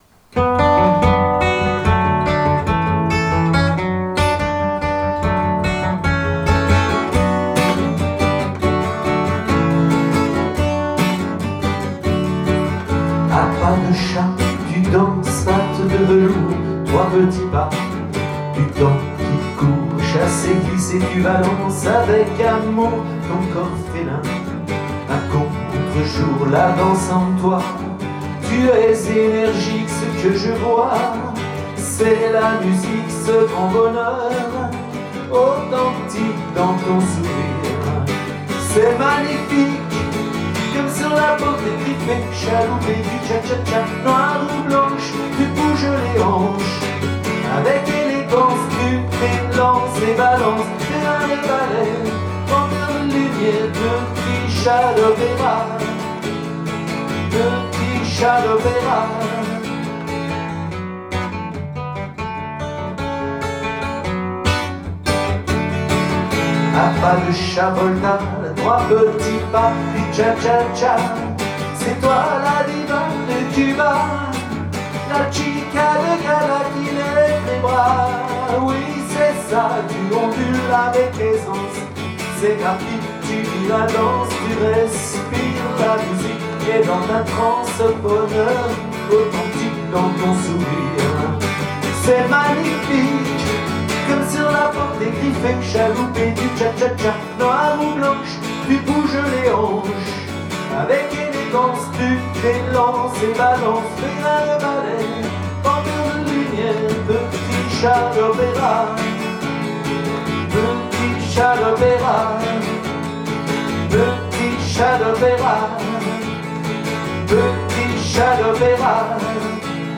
Cha cha cha mettant en valeur une danseuse charismatique.